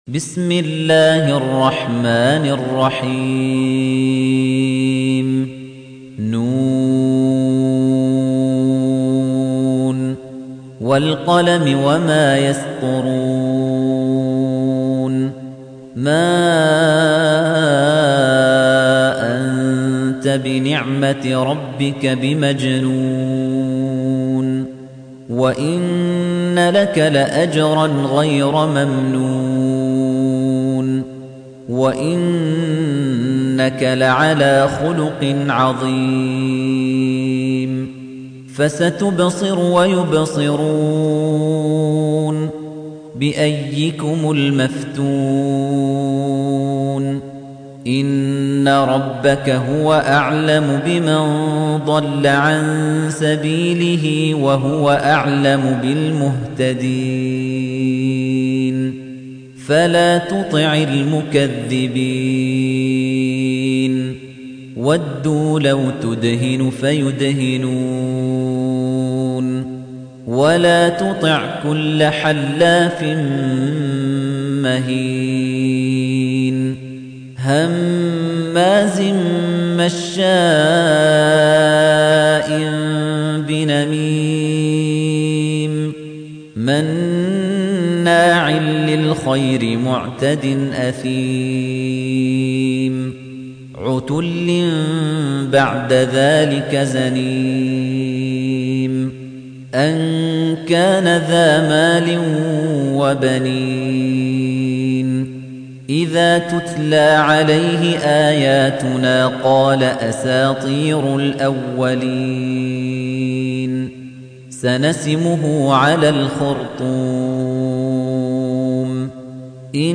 تحميل : 68. سورة القلم / القارئ خليفة الطنيجي / القرآن الكريم / موقع يا حسين